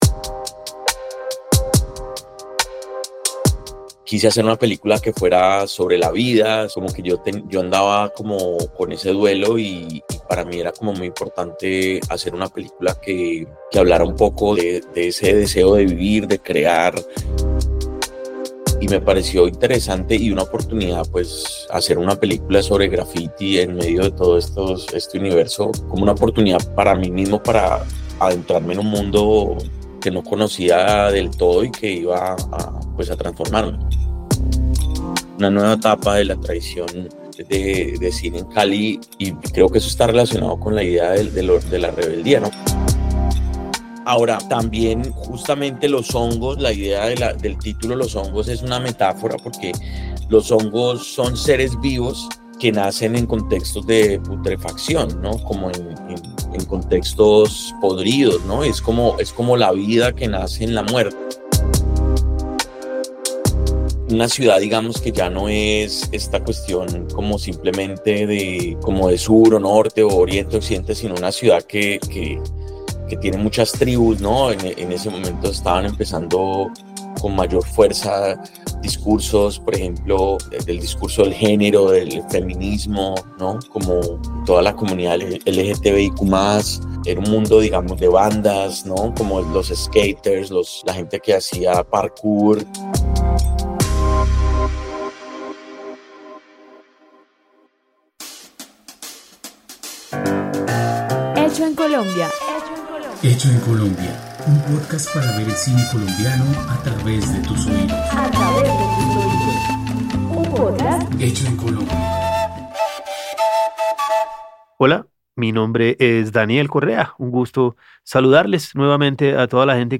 En una Cali convulsa de principios del siglo XXI, dos adolescentes buscan redención y libertad a través del arte y la rebeldía. Una conversación